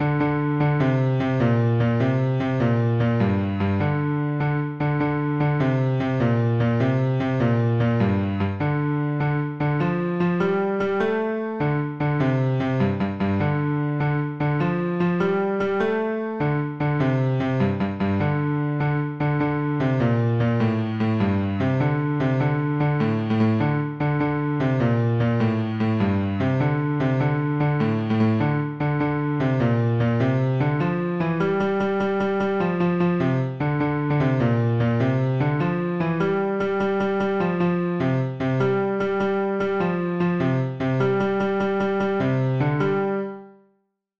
Bass Part